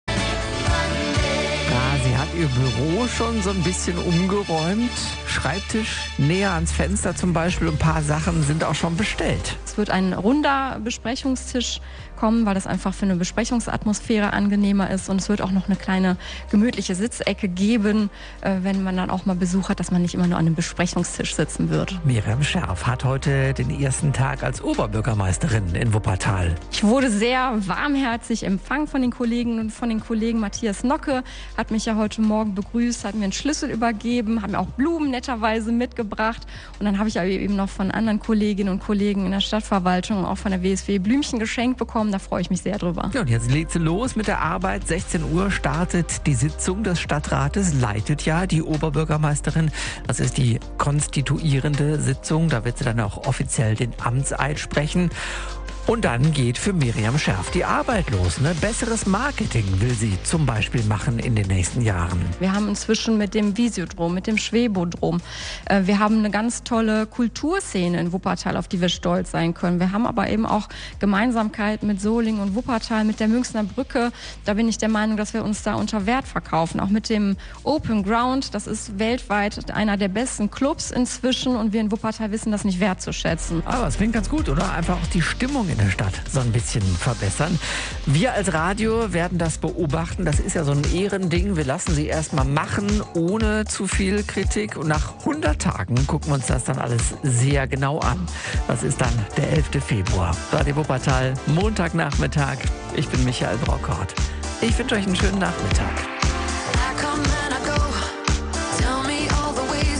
Sie sei nervös und aufgeregt, sagte sie im Radio-Wuppertal-Interview. Sie will das Oberbürgermeister-Büro und Teile der Stadtverwaltung umstrukturieren.